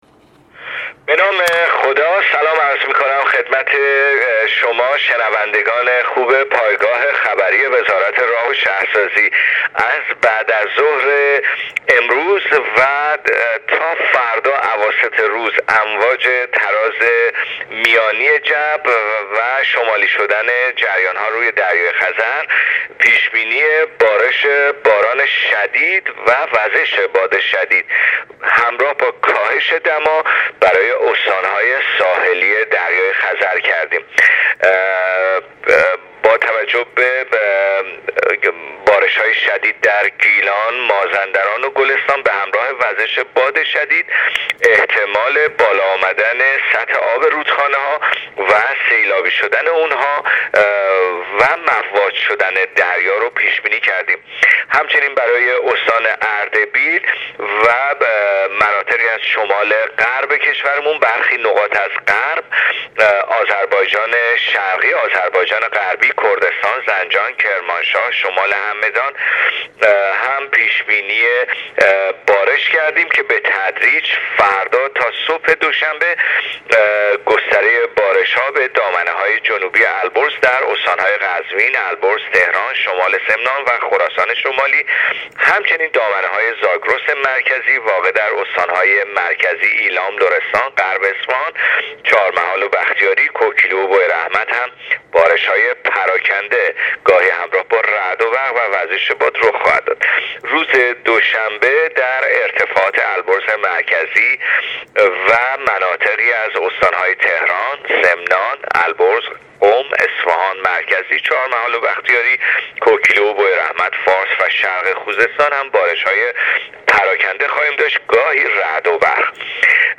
گزارش رادیو اینترنتی وزارت راه و شهرسازی از آخرین وضعیت آب و هوای ۲۰ مهر/ دمای هوا در استان‌های شمالی کاهش می‌یابد/مناطقی از غرب کشور بارانی می‌شود